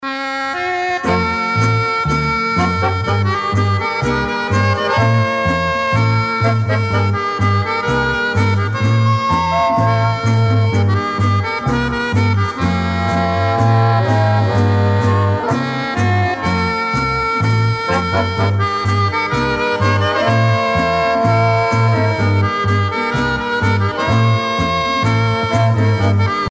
tango musette
Pièce musicale éditée